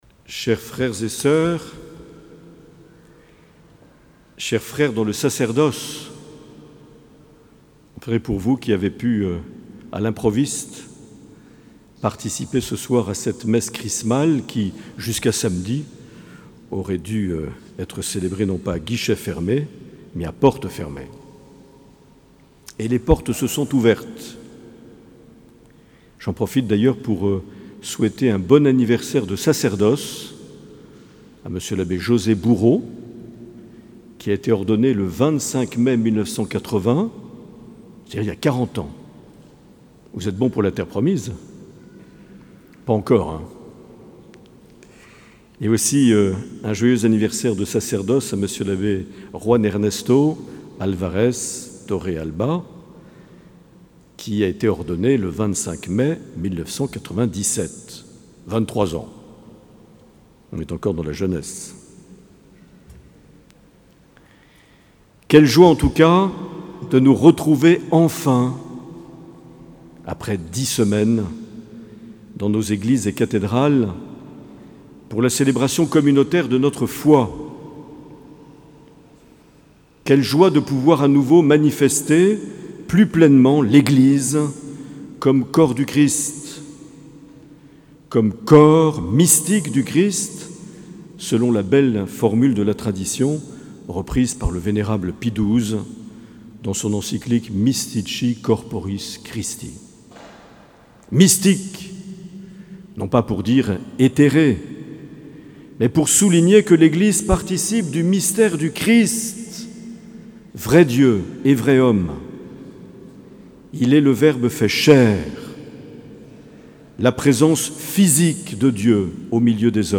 25 mai 2020 - Cathédrale de Bayonne - Messe Chrismale
Revivez la Messe Chrismale du 25 mai 2020.